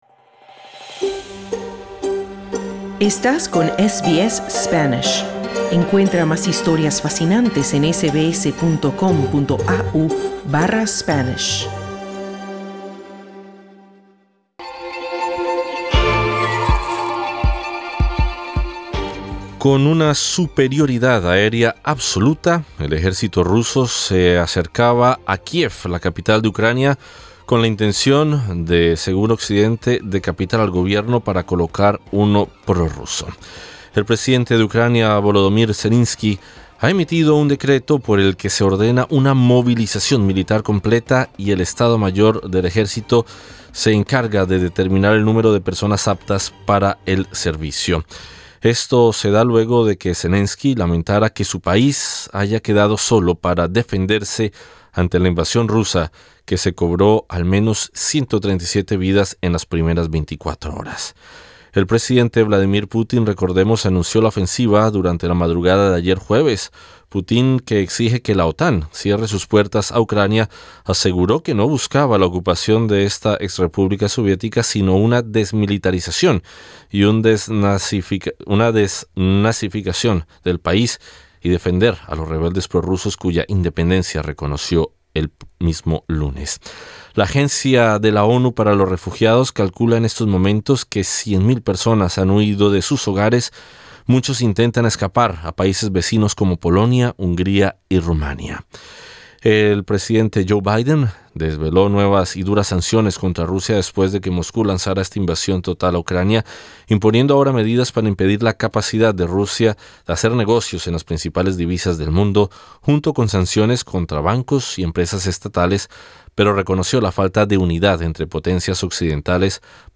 En SBS Spanish recogimos testimonios de hispanoparlantes en Kiev.